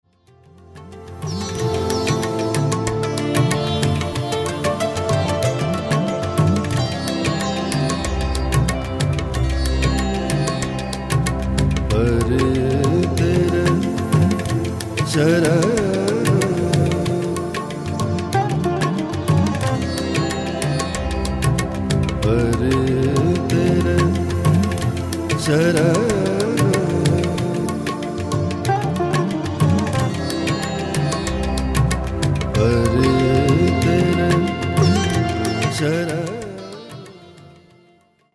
Stylowa wokaliza odkryje najgłębszą otchłań wyobraźni.